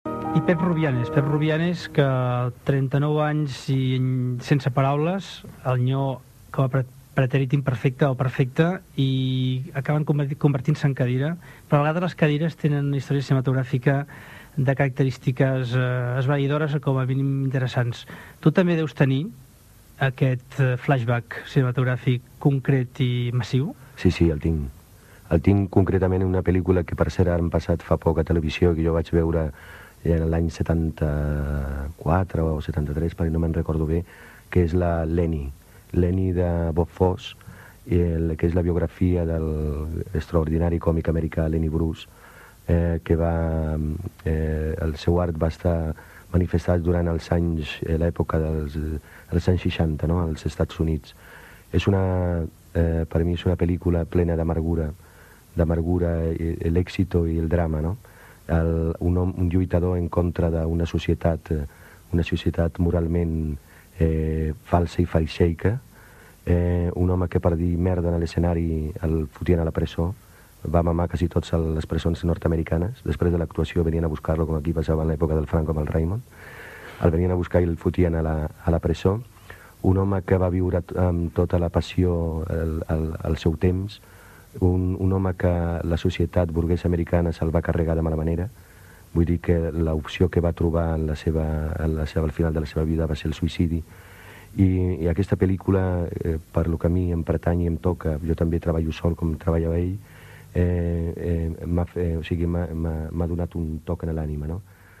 A la secció de cinema, l'actor Pepe Rubianes parla d'una pel·lícula que recorda especialment
Entreteniment